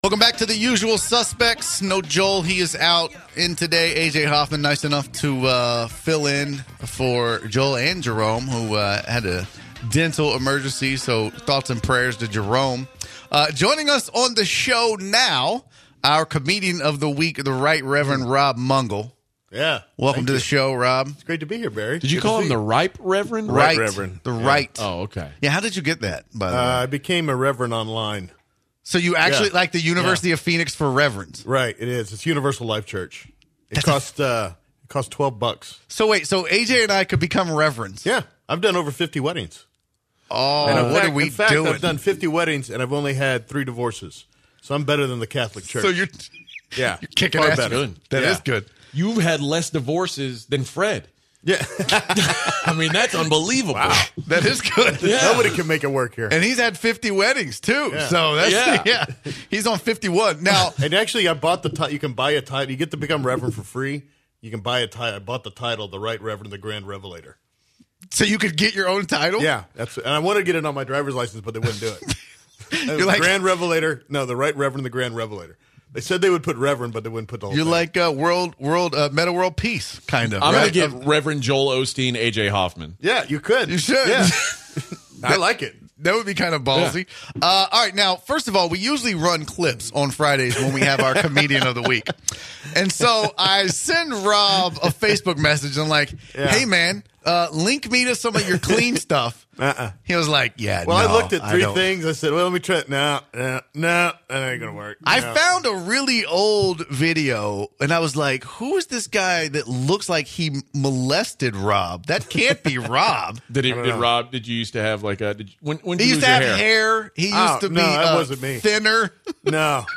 Standup comedian